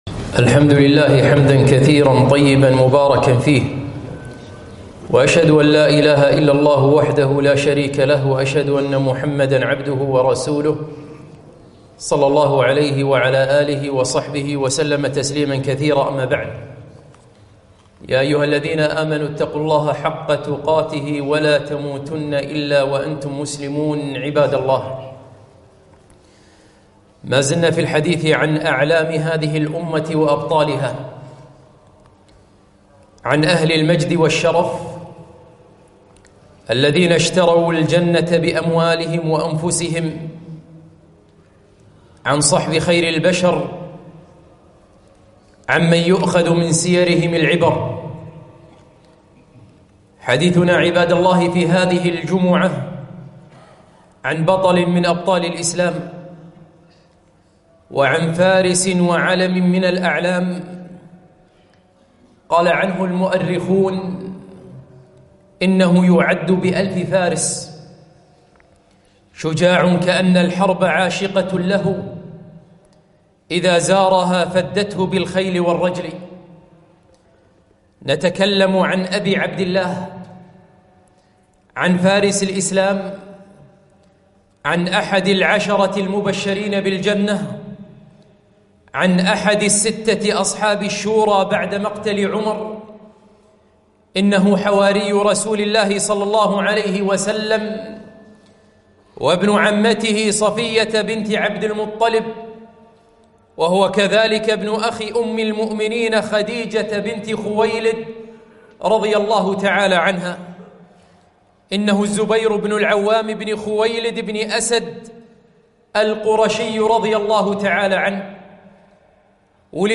خطبة - الزبير بن العوام رضي الله عنه